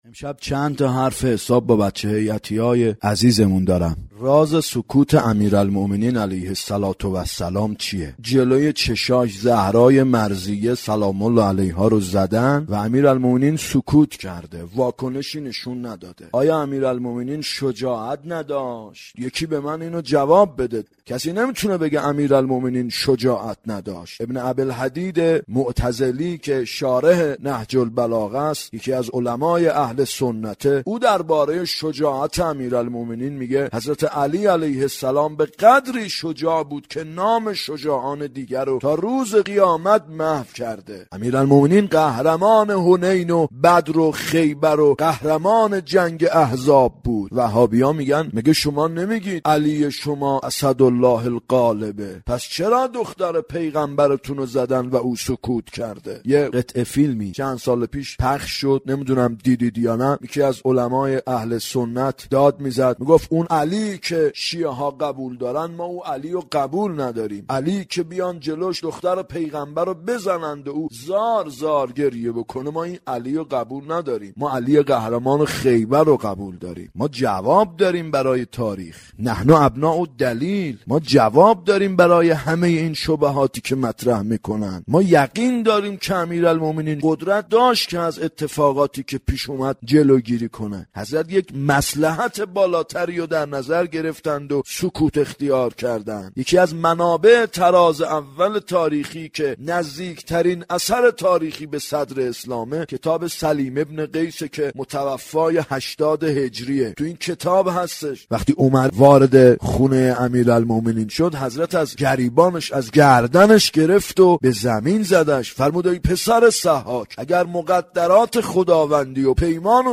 راه بی پایان سخنرانی 93